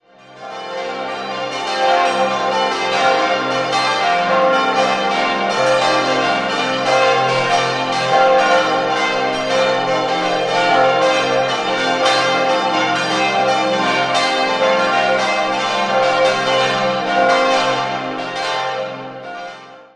Jedes Jahr findet hier auch der Bayerische Evangelische Kirchentag statt. 6-stimmiges Westminster-Geläute: c'-f'-g'-a'-c''-d'' Alle Glocken wurden 1960 in leichter Rippe von Friedrich Wilhelm Schilling in Heidelberg hergestellt.